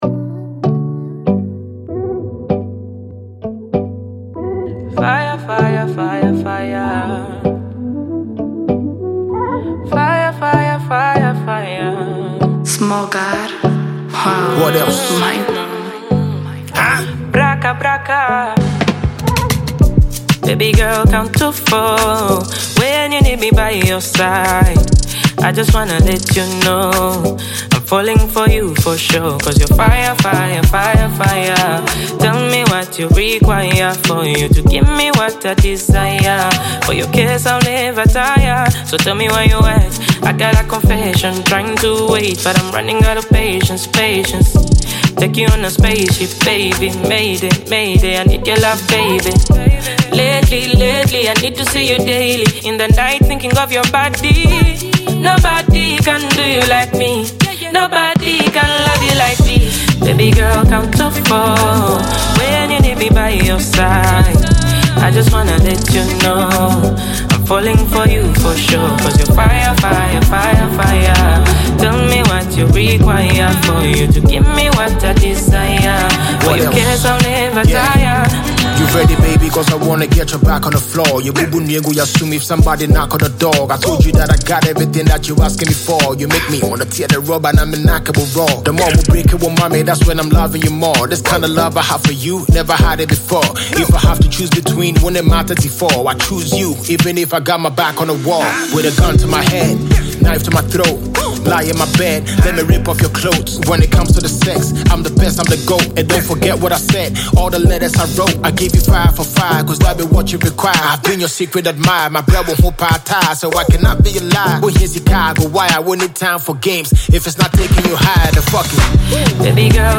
a powerful fusion of Afrobeat, hip-hop, and soulful melodies
soothing vocals and an infectious hook